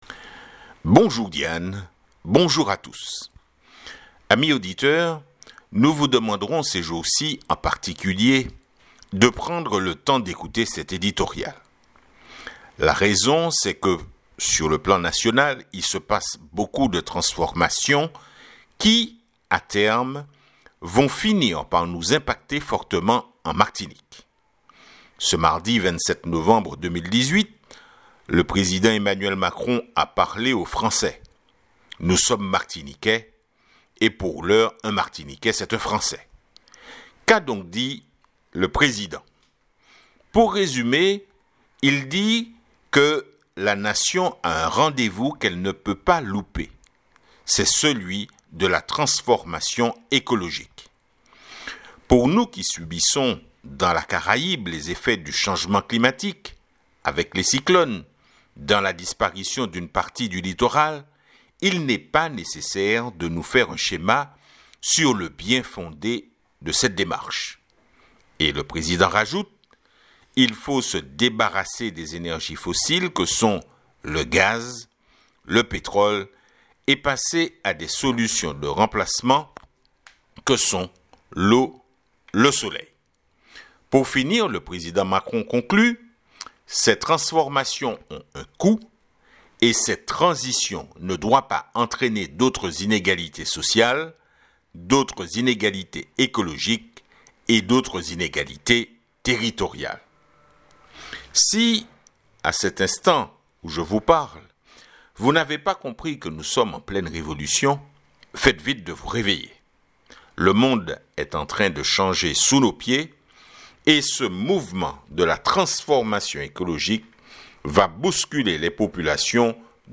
Editorial du Jour / La nation a un rendez vous qu’elle ne peut pas louper, c’est la transformation écologique.